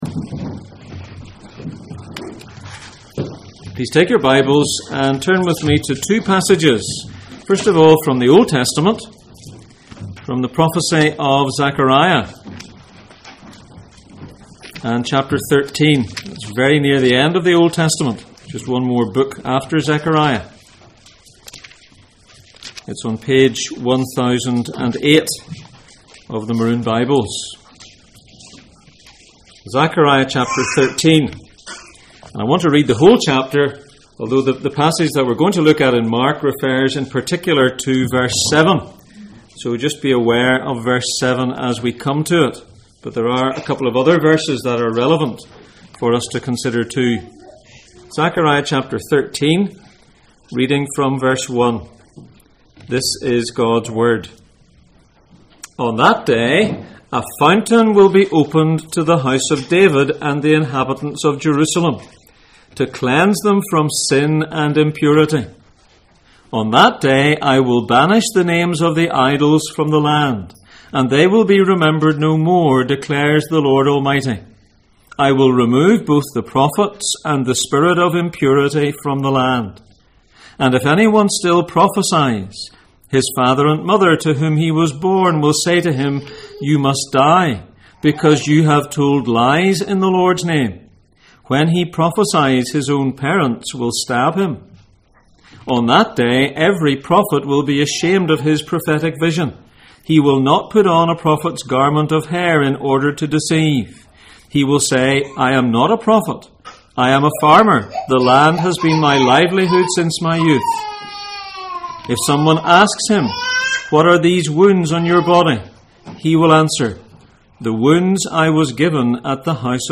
Zechariah 13:1-9 Service Type: Sunday Morning %todo_render% « No more enemies Psalm 40 »